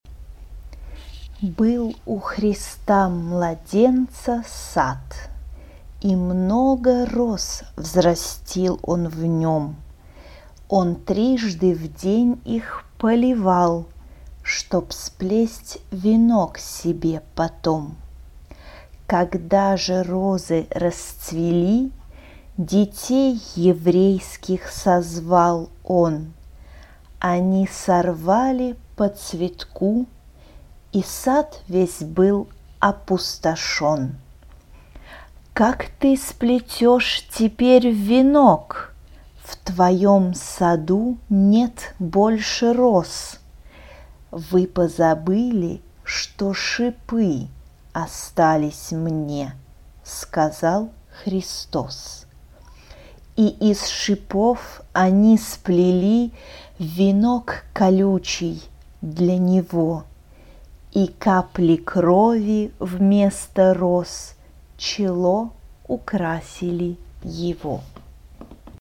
for mixed choir a cappella
Genre-Style-Form: Secular ; Choir Mood of the piece: pensive ; serious ; solemn Type of Choir: SATB (4 mixed voices )
Tonality: E minor